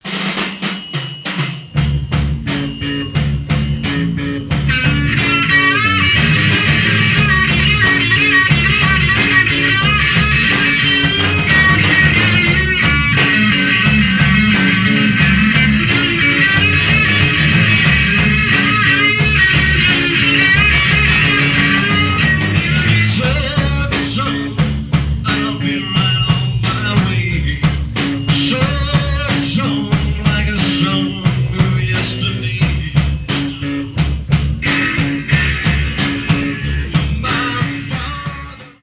Heavy Metal Rock.